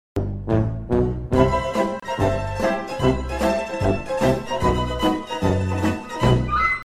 Звуки для монтажа видео